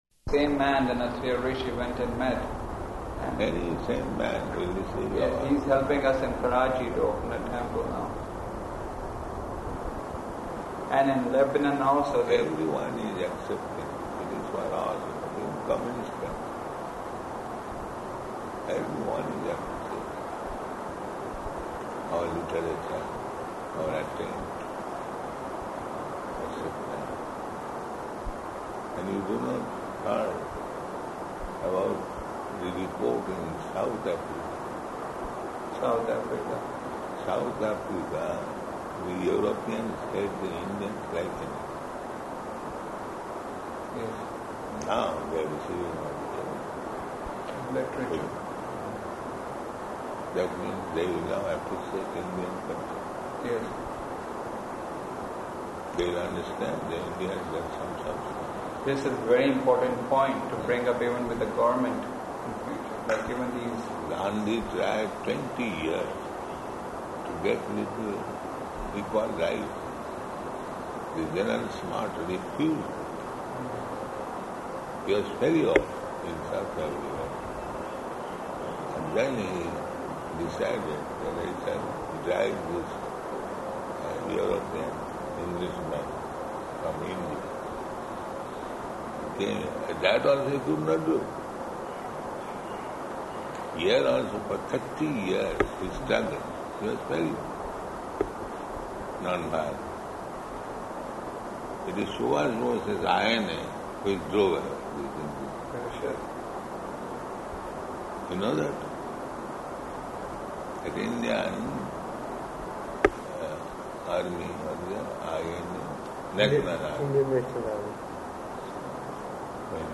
Room Conversation
-- Type: Conversation Dated: August 8th 1977 Location: Vṛndāvana Audio file